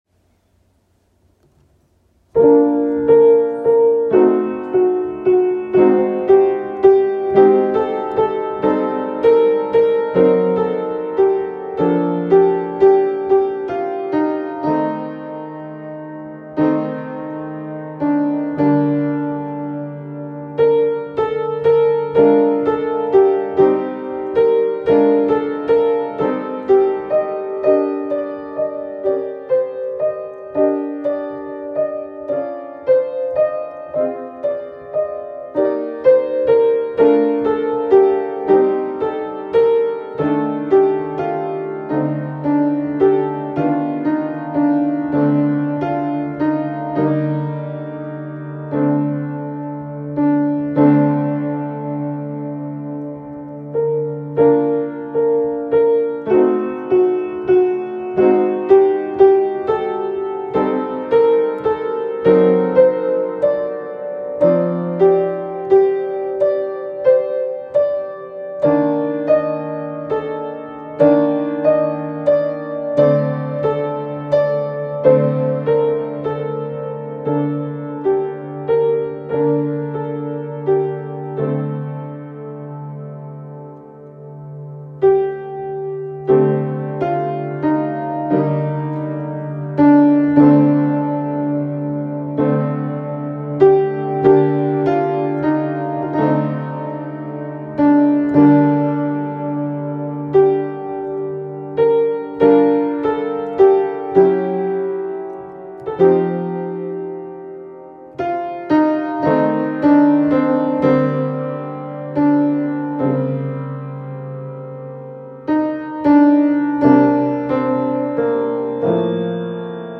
improvisation 2 - Piano Music, Solo Keyboard - Young Composers Music Forum
I’m trying to improve at improvising, I feel like currently I repeat the same ideas too much.